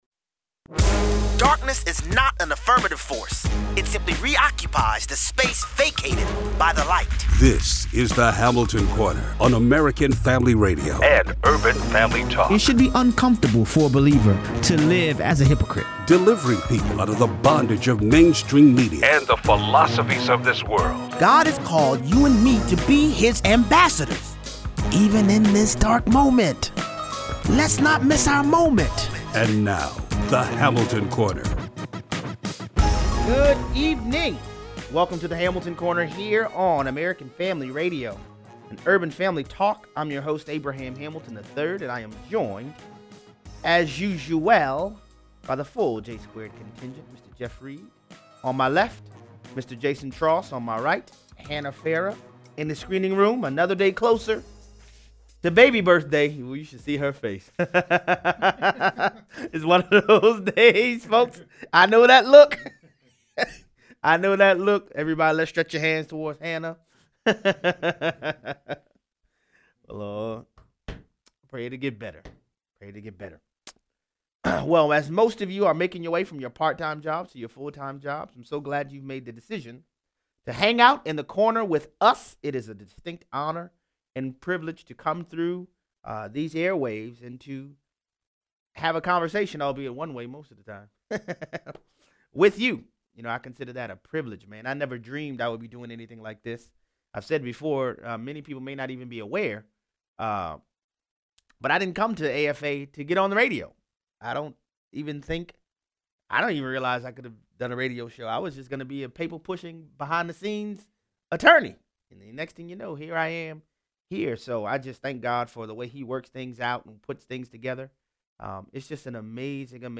Once the Mueller Report was released MSNBC and CNN’s ratings plummet. 0:38 - 0:55: CBS portrays human smugglers as a humanitarians. Callers weigh in.